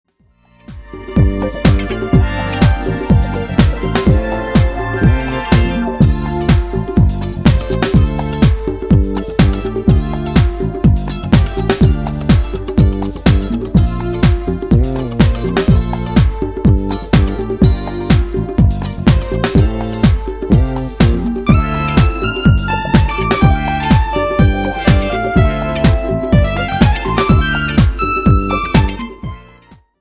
JAZZ  (02.33)